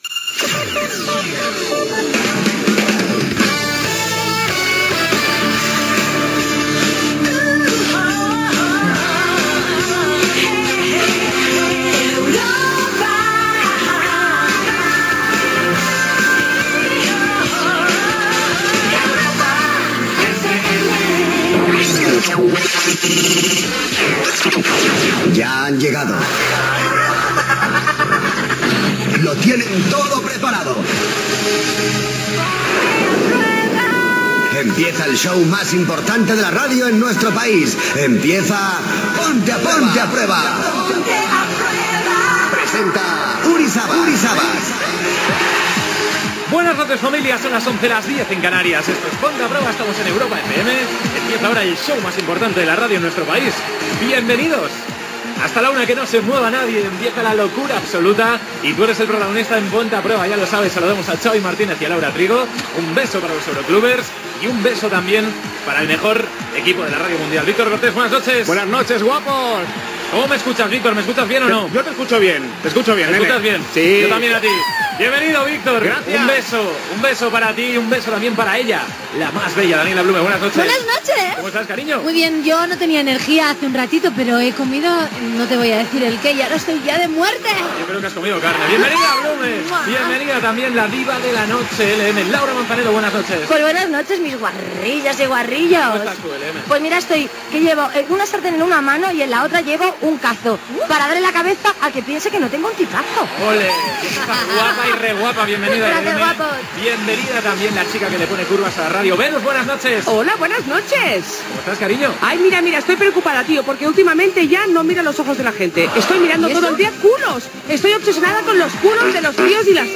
Indicatiu de la ràdio, careta, presentació de l'equip, indicatiu del programa, formes de contactar, intervenció de Dani Mateo, missatges de l'audiència,
Entreteniment